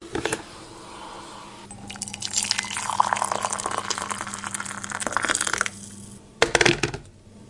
厨房 " Položení konvice
描述：Pokládáníkonvicenazákladnu。
标签： 厨房 水壶
声道立体声